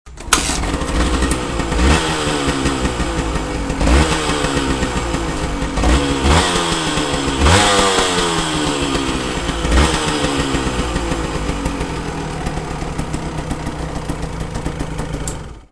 Soundfile des CRM-Sounds, MP3, 123KB
- 1-Zylinder-Zweitaktmotor